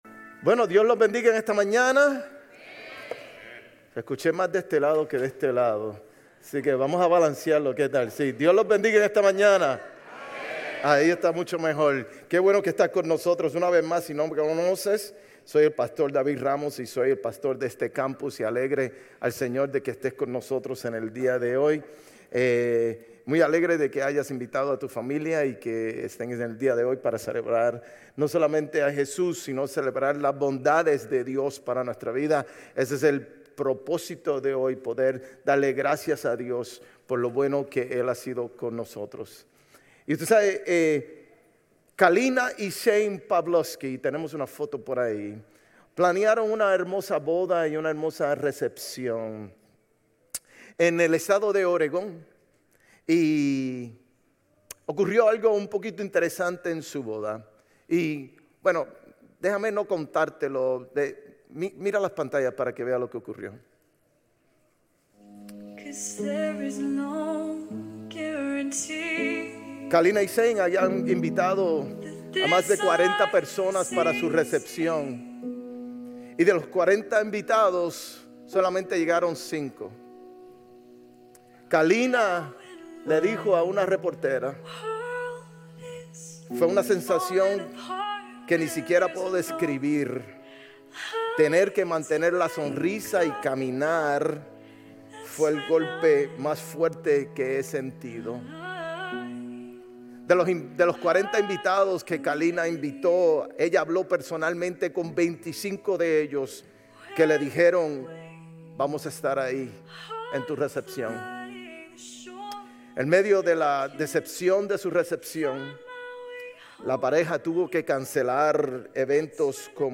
Sermones Grace Español 11_9 Grace Espanol Campus Nov 10 2025 | 00:41:21 1x 00:00 / 00:41:21 Subscribe Share RSS Feed Share Link Embed